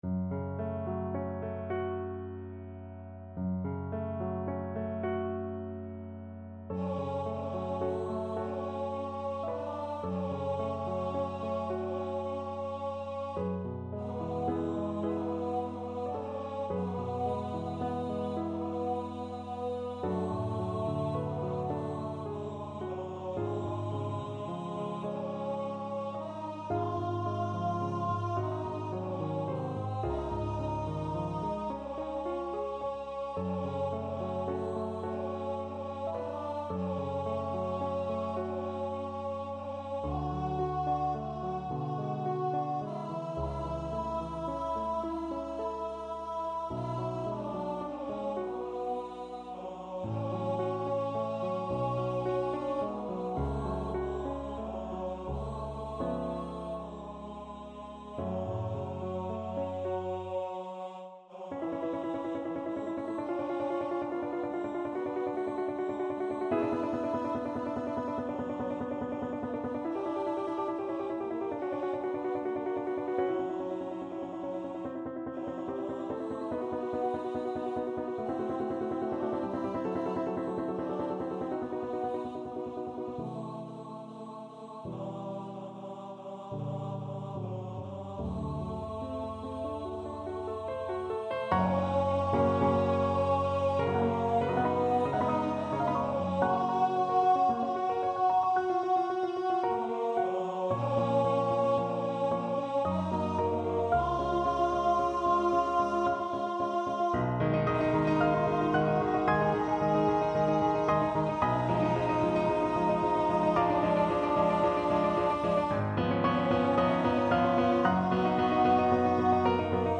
Free Sheet music for Tenor Voice
C major (Sounding Pitch) (View more C major Music for Tenor Voice )
Andantino =108 (View more music marked Andantino)
6/8 (View more 6/8 Music)
Tenor Voice  (View more Advanced Tenor Voice Music)
Classical (View more Classical Tenor Voice Music)